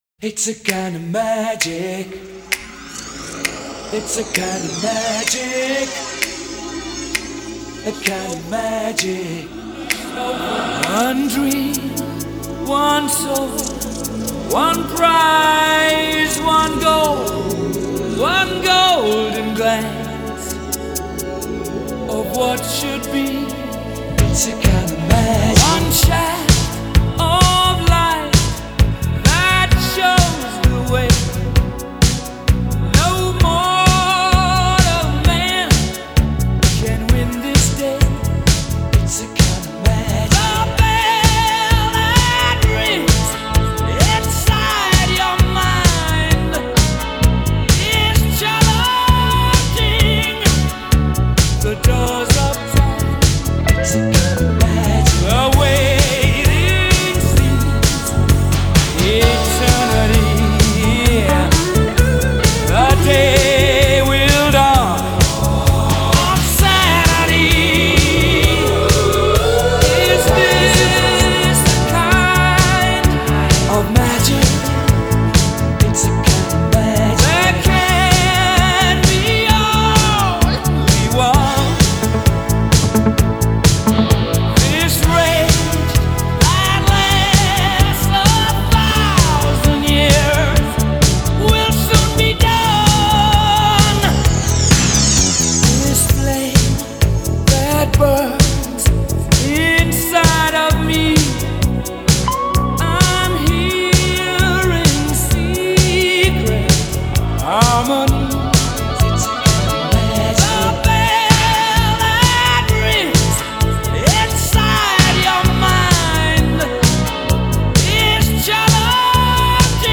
راک Rock